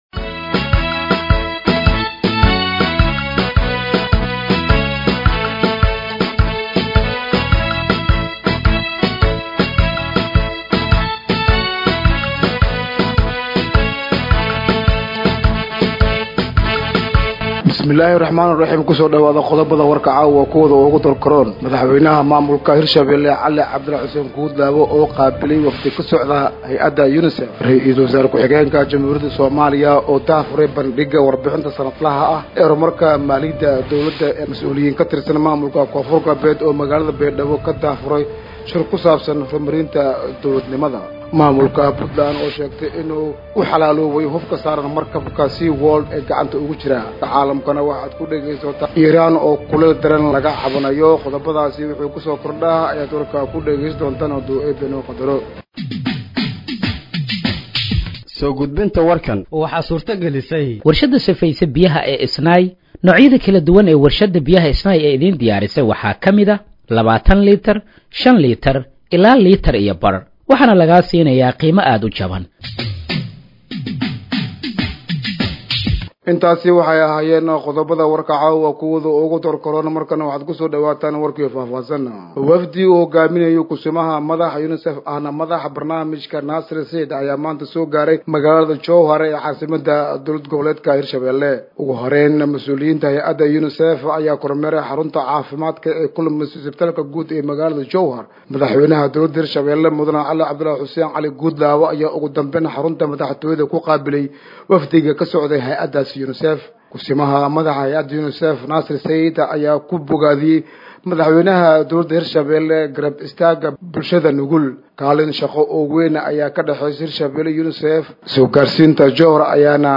Dhageeyso Warka Habeenimo ee Radiojowhar 23/07/2025